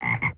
croak.wav